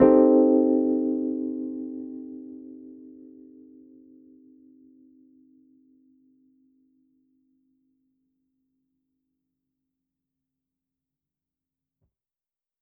JK_ElPiano3_Chord-Cm6.wav